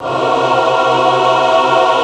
CHOIR DIM.wav